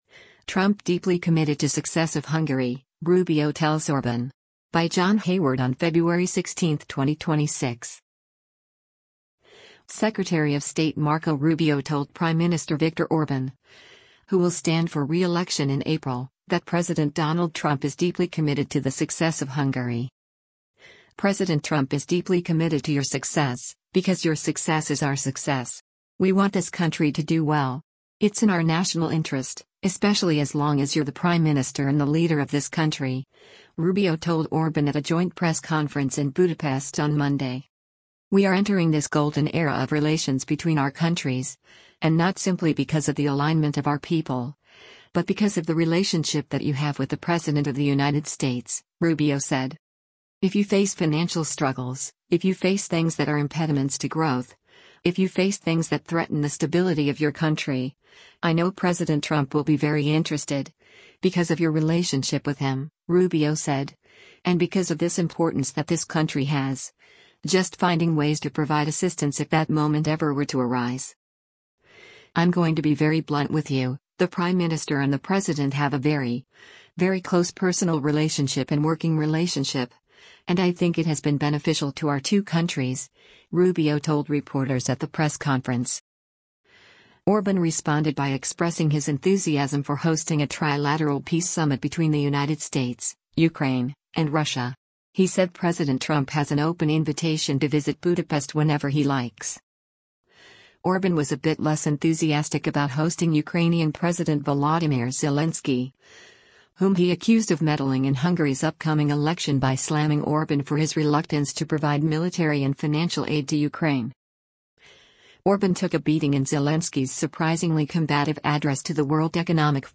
“President Trump is deeply committed to your success, because your success is our success. We want this country to do well. It’s in our national interest, especially as long as you’re the prime minister and the leader of this country,” Rubio told Orban at a joint press conference in Budapest on Monday.